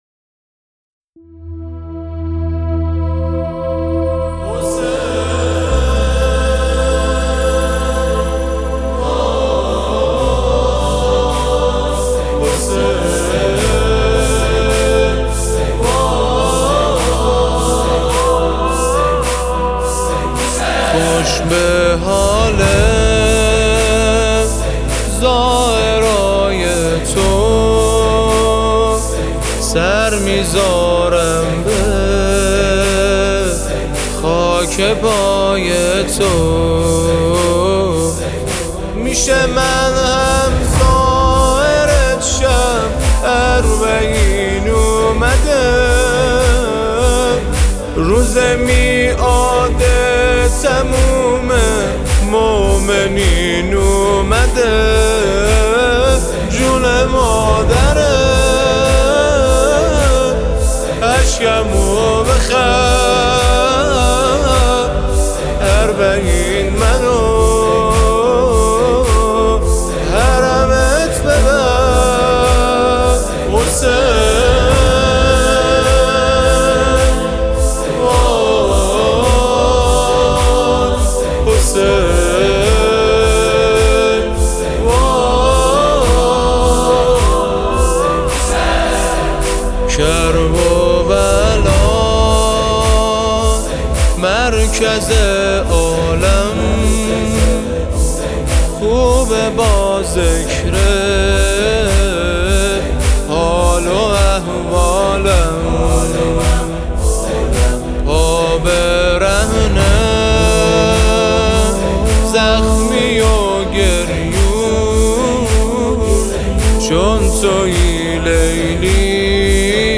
تهیه شده در استودیو نجوا